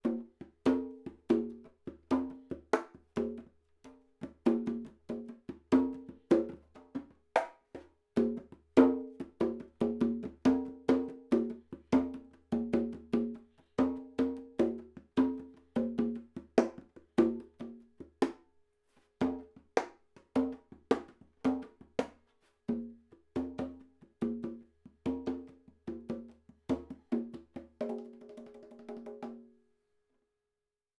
We used about 50%+50% mix for Mid-Side decoding.
Mid_Side
1/2″ Condenser Cardioid, 1/4″ condenser Omni.
MS Congas
MS_Congas.wav